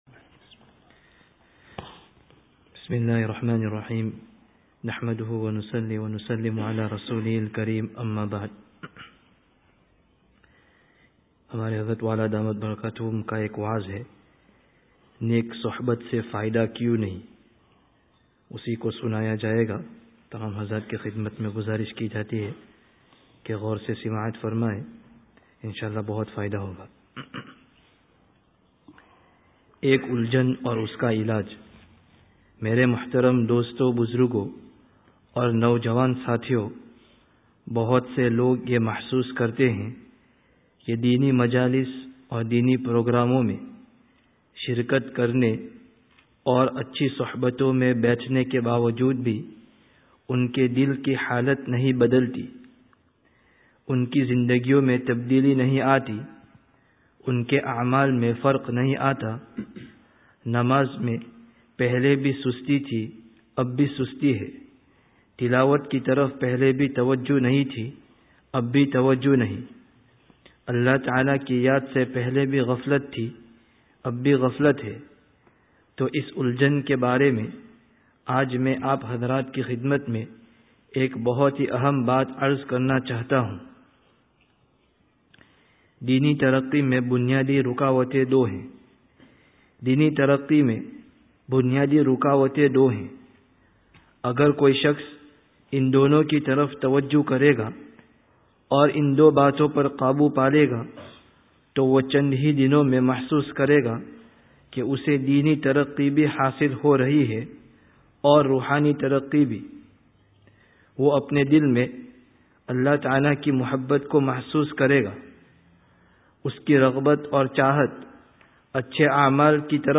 Download Urdu 2013 wa'z bil-kitab friday tazkiyah gathering Related articles Wa'z Bil-Kitāb: Neyk Suhbat se Fā'idah kyu(n) nahi(n)?